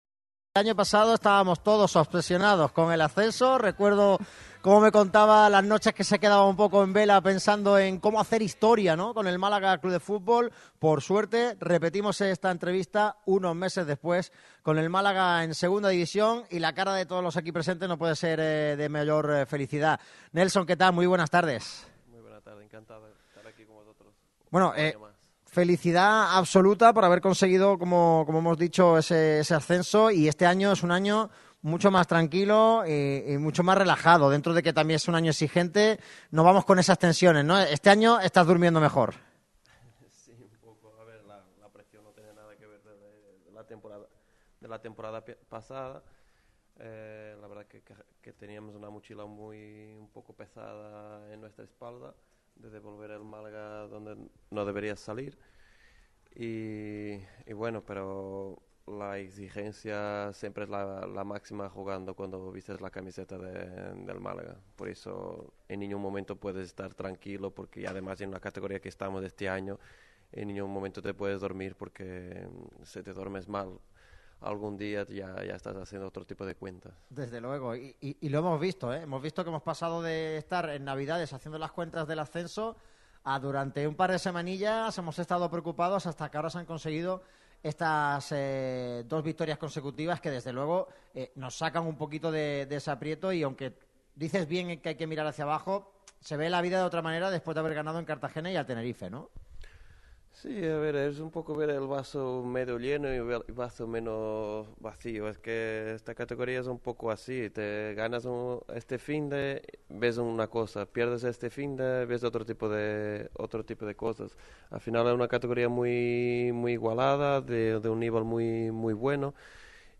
Lee y escucha la entrevista del central luso.
El defensa del Málaga CF, Nelson Monte, ha atendido a Radio MARCA Málaga en el programa de este jueves en exclusiva. El central portugués ha repasado toda la actualidad del equipo y, por supuesto, su actual estado de forma tras recuperarse en tiempo récord de una lesión en la rodilla derecha.